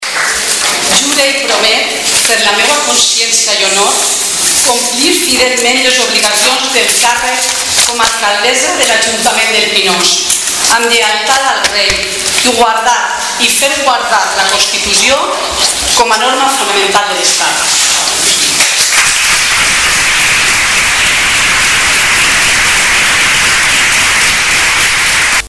Las primeras palabras de Silvia Verdú han sido de agradecimiento al concurrido público que abarrotaba el Salón de Sesiones de la Casa de Don Pedro, entre quienes figuraban familiares, miembros de asociaciones y de la ciudadanía de Pinoso, además de numerosas autoridades como los diputados nacionales Alejandro Soler y Lázaro Azorín (su predecesor en el cargo), el secretario de Organización del PSPV, Vicent Mascarell, la diputada autonómica Charo Navalón, el diputado provincial Francis Rubio, el alcalde de Monóvar, Loren Amat, y su edil de Educación, Xavier Guardiola, el Alcalde de la Romana, Nelson Romero, y el concejal de Educación, Enrique Rizo.